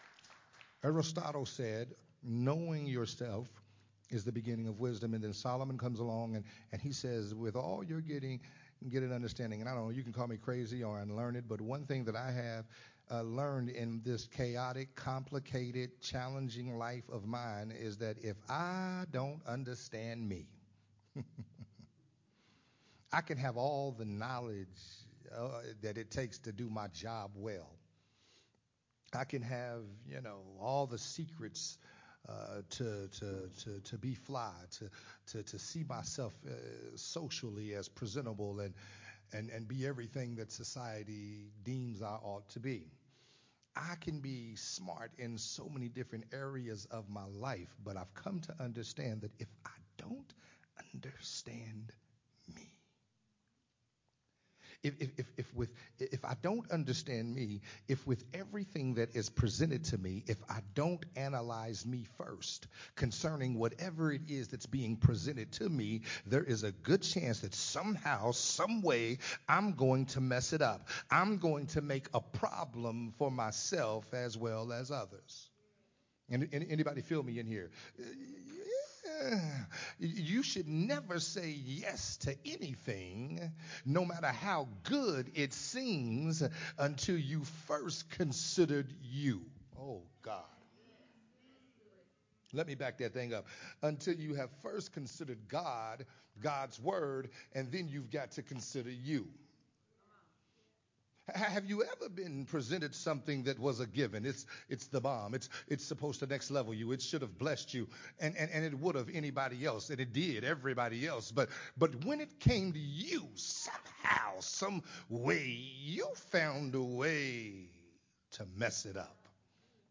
recorded at Unity Worship Center on April 23rd, 2023.
sermon series